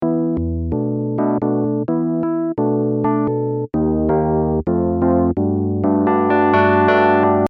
Stumbled upon this when writing arrangements for a acid jazz dance funk track. Don't know the name of the last chords but they are fun.